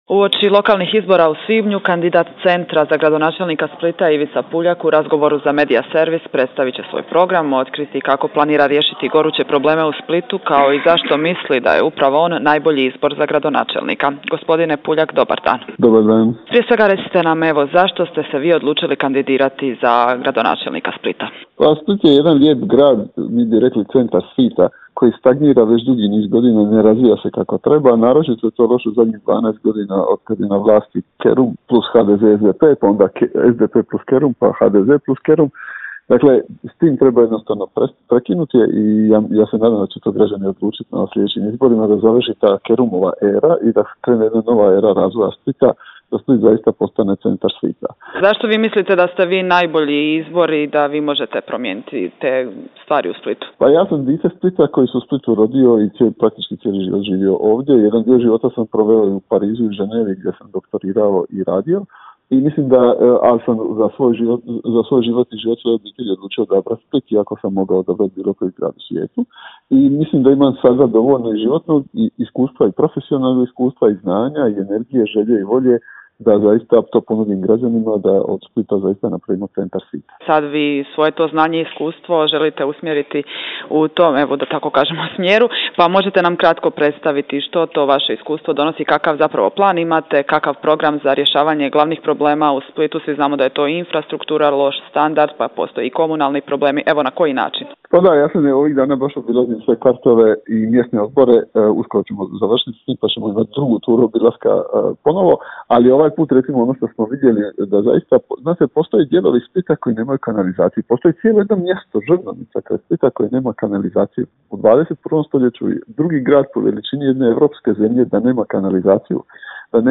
ZAGREB - Uoči lokalnih izbora u svibnju kandidat Centra za gradonačelnika Splita Ivica Puljak u razgovoru